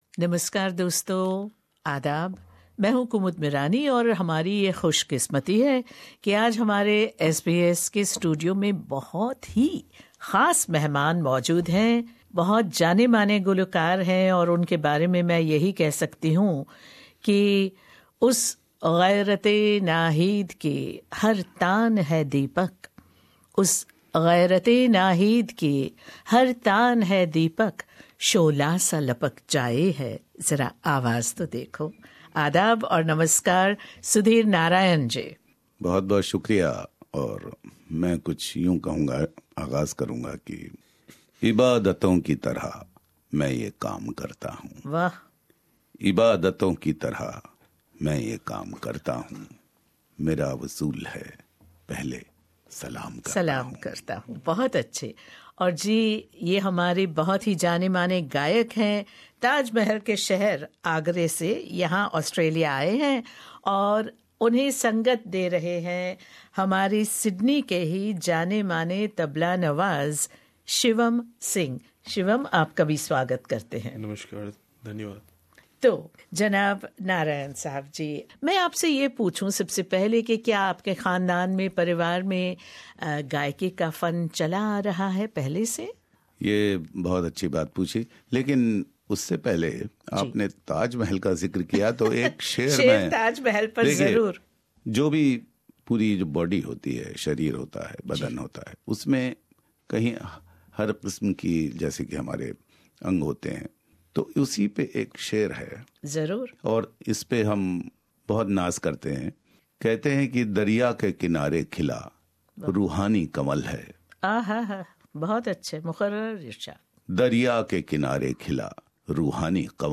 an outstanding ghazal singer from India
interview
hear him sing live in the SBS Studio
Tabla